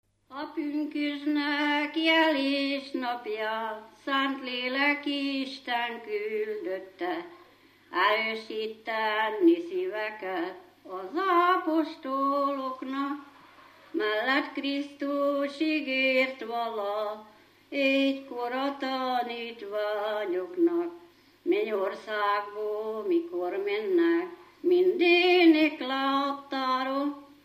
Dunántúl - Moson vm. - Kisbodak
ének
Stílus: 2. Ereszkedő dúr dallamok